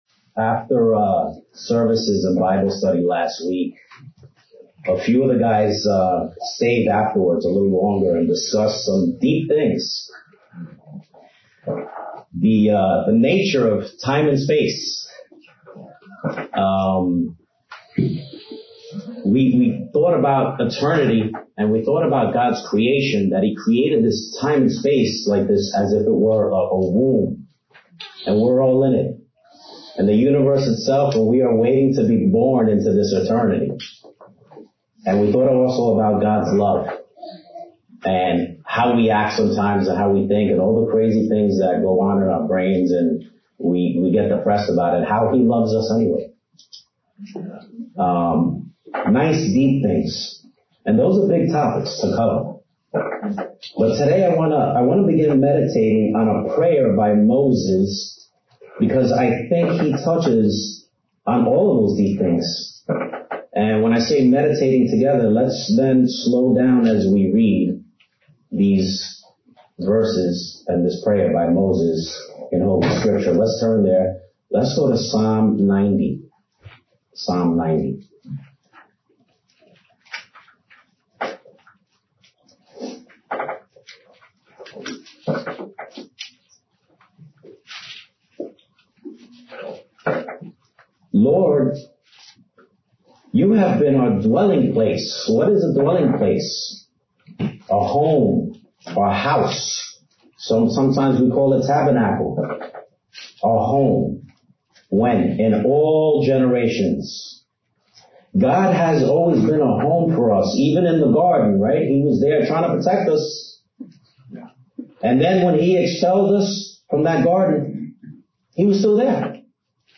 Given in New Jersey - North New York City, NY